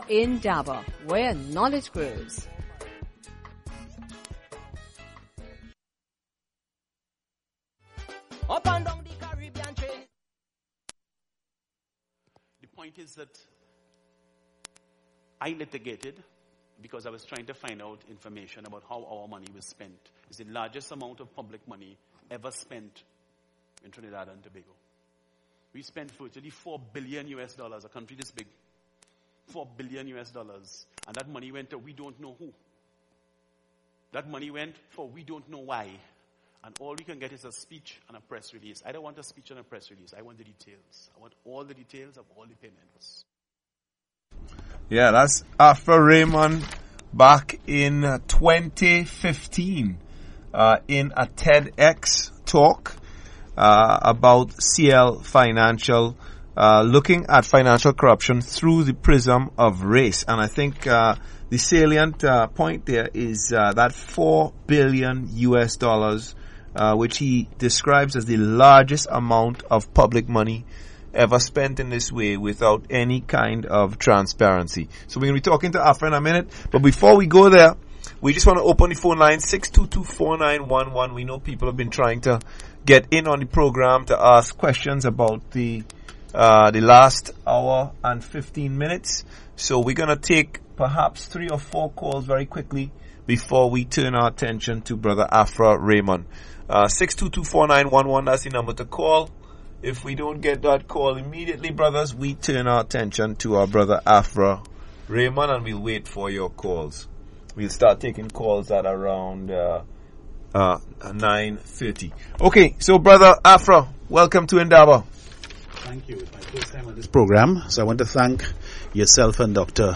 AUDIO: INDABA interview